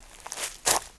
High Quality Footsteps / Leaves / Misc
MISC Leaves, Foot Scrape 02.wav